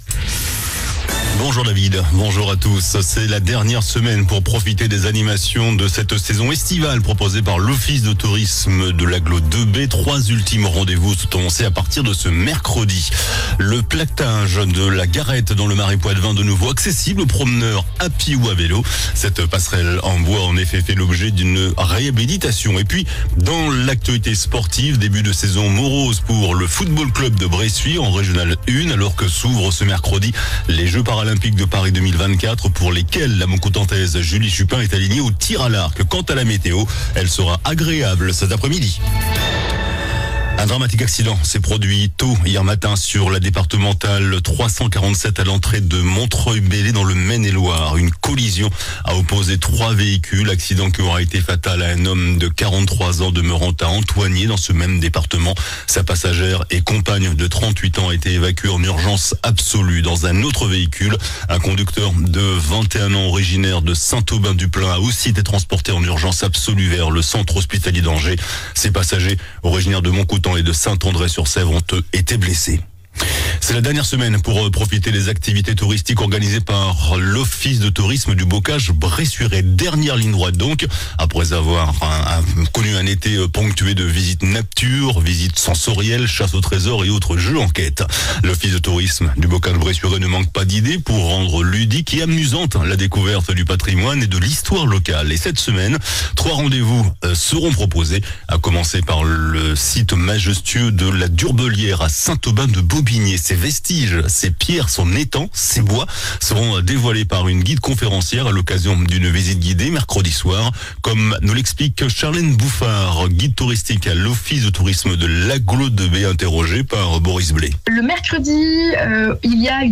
JOURNAL DU LUNDI 26 AOÛT ( MIDI )